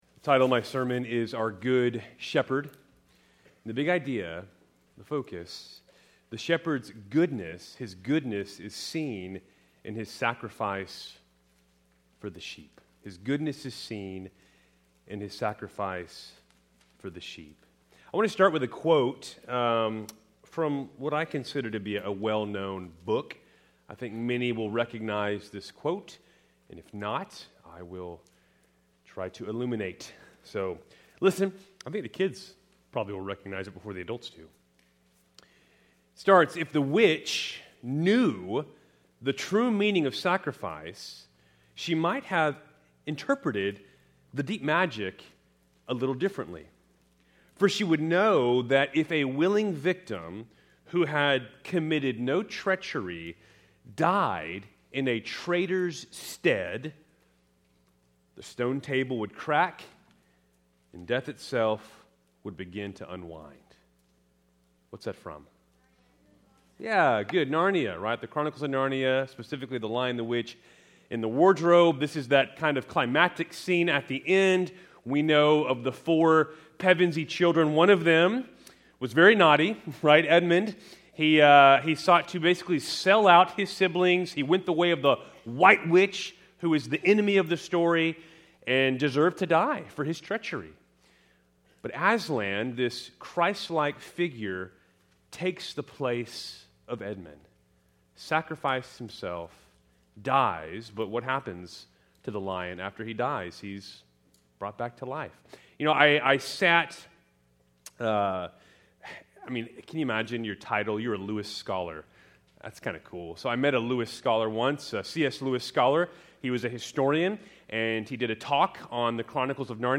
Keltys Worship Service, January 19, 2024.